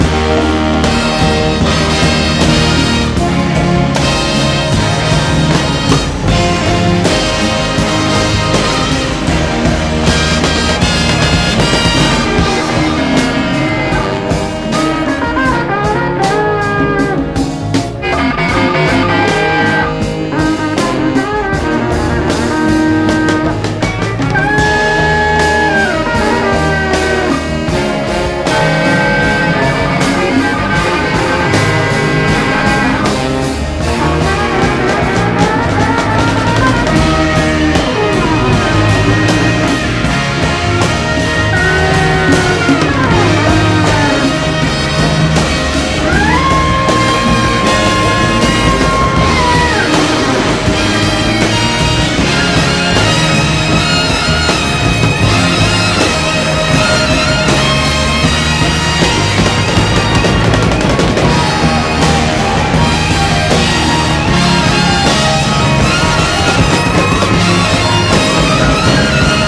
DJユースなヨーロピアン・レアグルーヴ/自主系ジャズを集めたナイス・コンピ！